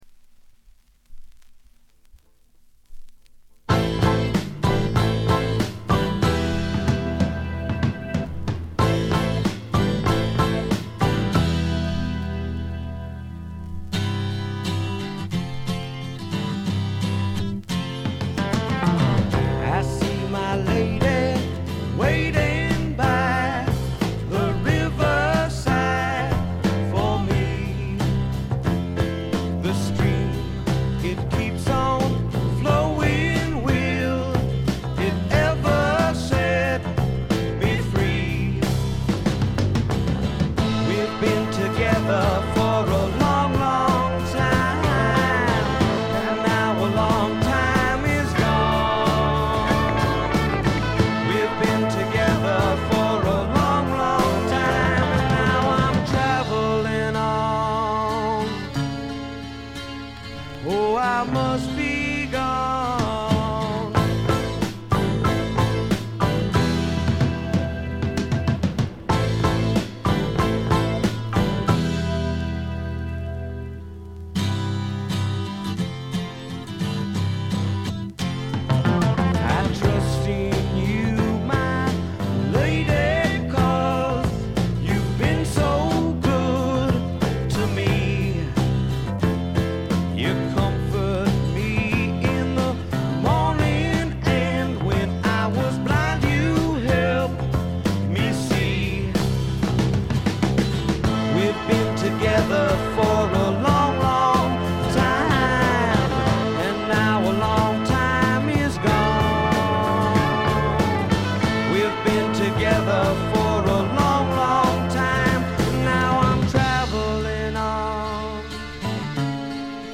軽微なバックグラウンドノイズ、チリプチ程度。
知られざるスワンプ系シンガー・ソングライターの裏名盤です。
試聴曲は現品からの取り込み音源です。